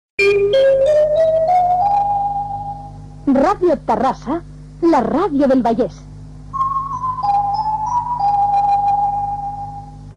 3a053850de0820cc9e5c275a86843e91c858f129.mp3 Títol Ràdio Terrassa Emissora Ràdio Terrassa Cadena SER Titularitat Privada local Descripció Sintonia i identificació de l'emissora.